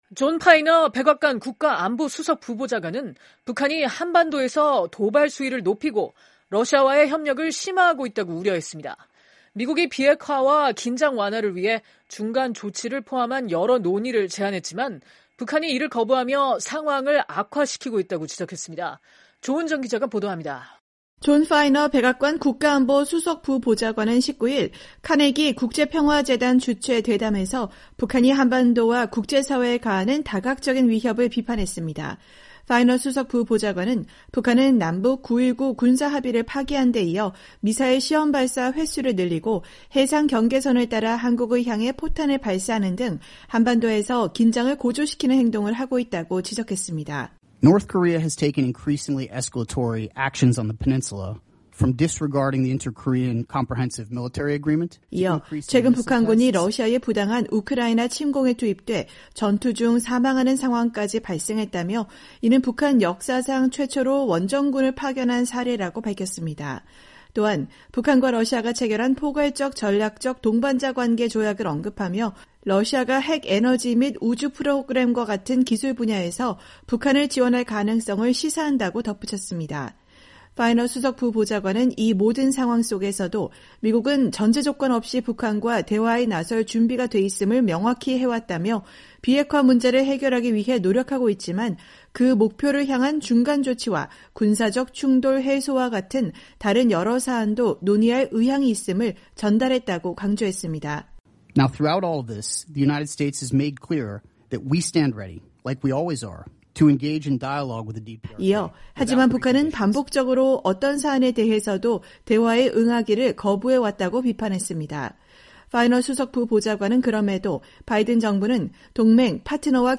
존 파이너 백악관 국가안보 수석부보좌관이 19일 카네기국제평화재단 주최 대담에서 연설하고 있다.